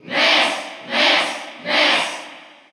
Category: Crowd cheers (SSBU) You cannot overwrite this file.
Ness_Cheer_Russian_SSBU.ogg